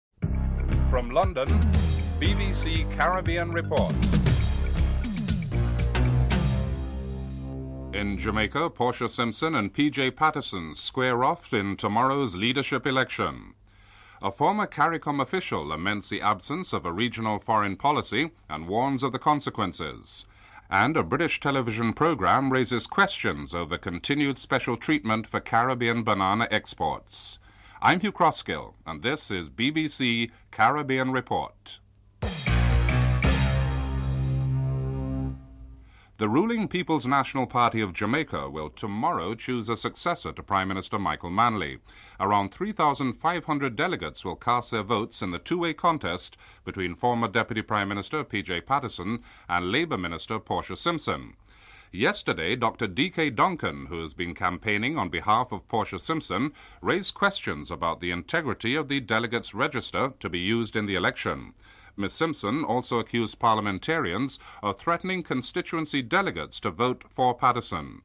1. Headlines (00:00-00:34)